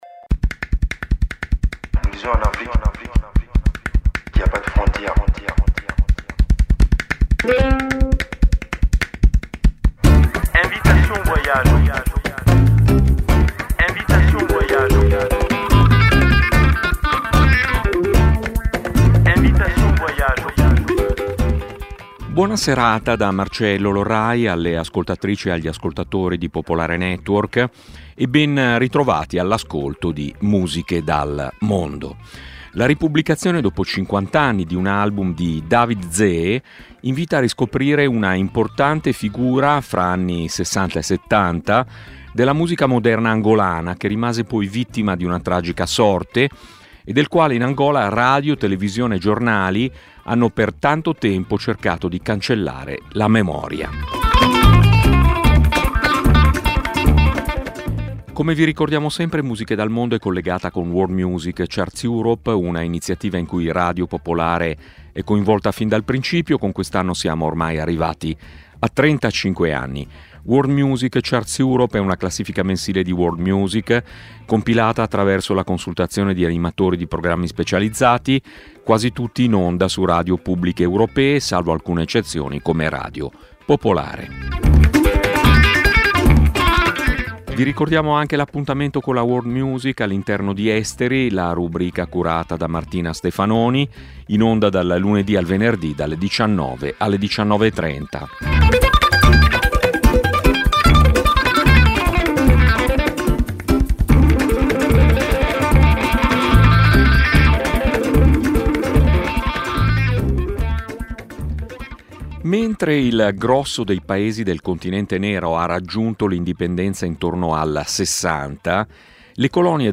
La trasmissione propone musica che difficilmente le radio mainstream fanno ascoltare e di cui i media correntemente non si occupano. Un'ampia varietà musicale, dalle fanfare macedoni al canto siberiano, promuovendo la biodiversità musicale.